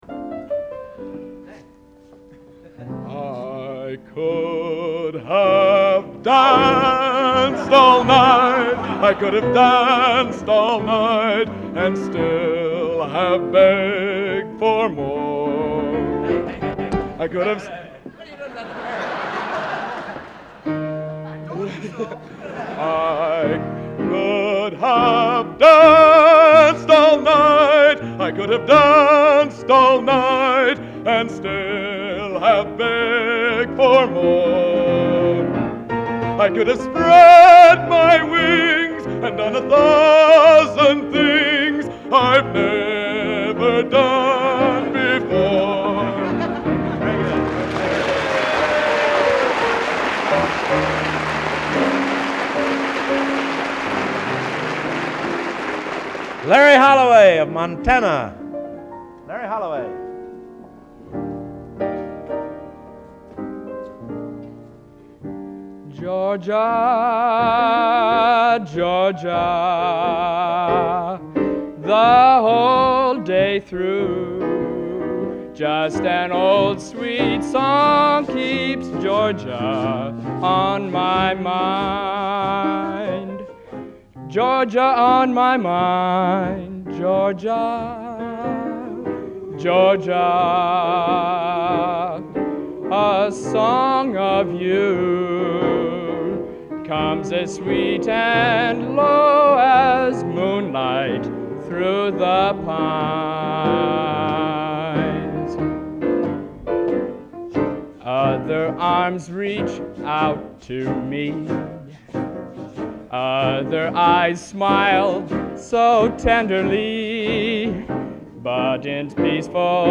Location: West Lafayette, Indiana
Genre: | Type: End of Season